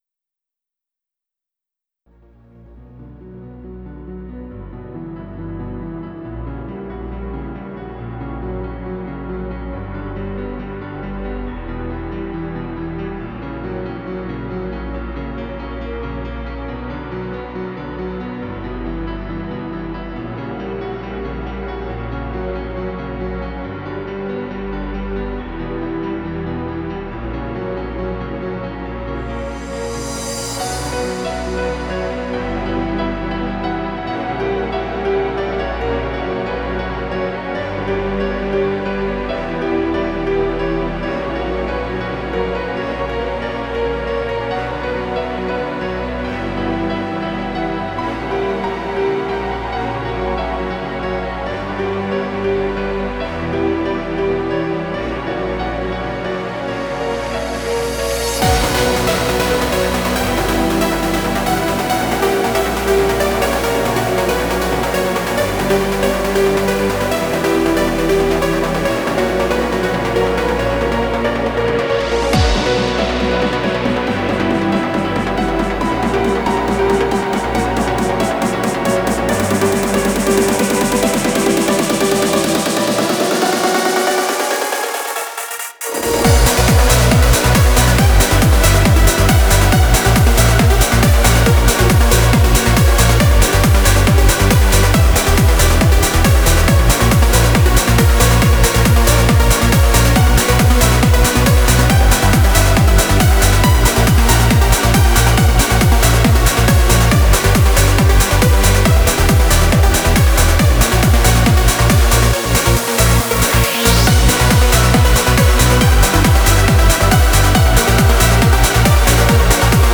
Genre: Trance / Uplifting